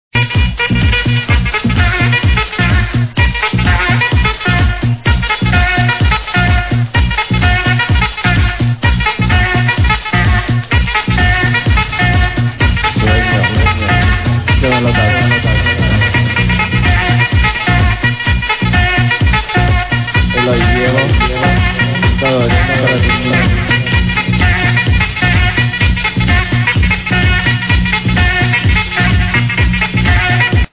bassline is familar